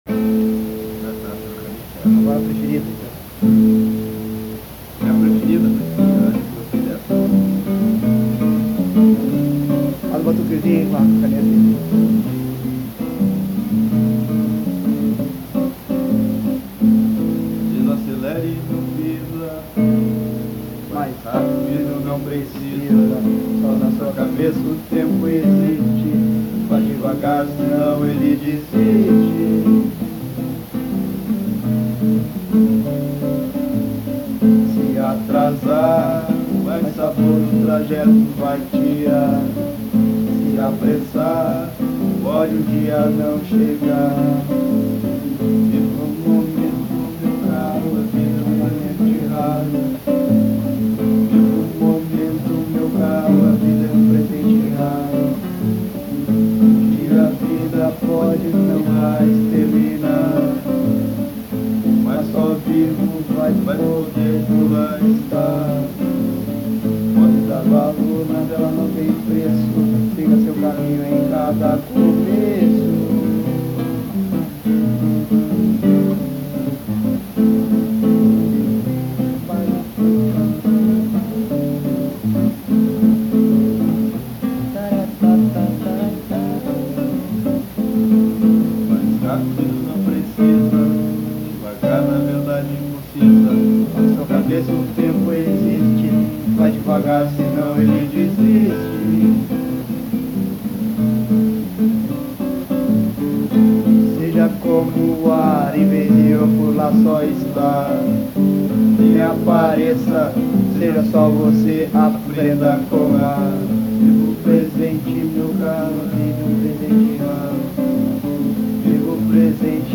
Musicas gravadas no momento de criação, realizadas no improviso, sem ensaio, sem estúdio ( faltam mais instrumentos em arranjos e ensaio, outras Letras melhores e mais importantes estão para terem acompanhamento de violão e ritmo, ou menos, ou mais, para virarem Novos Protótipos ou Esboços como base para Gravação )...